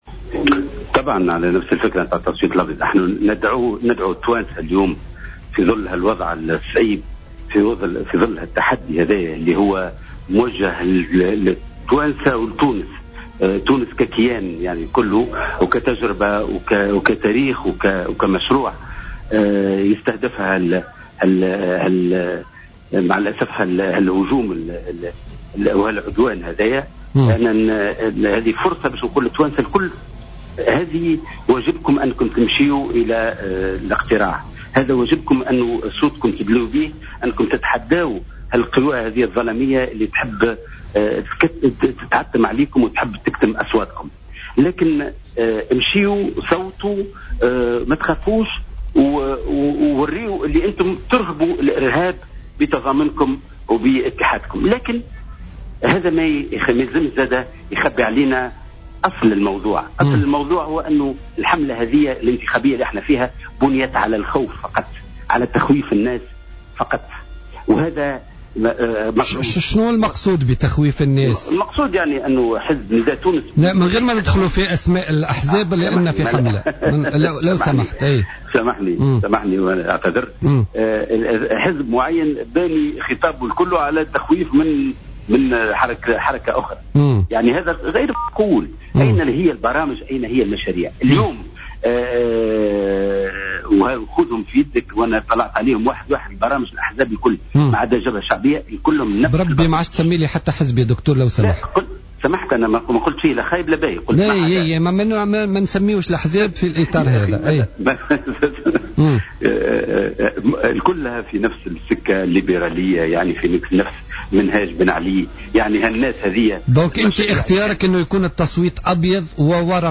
قال لطفي المرايحي الأمين العام للاتحاد الشعبي الجمهوري في مداخلة له اليوم الخميس في برنامج "بوليتيكا" إن الحملة الانتخابية بنيت على تخويف الناس.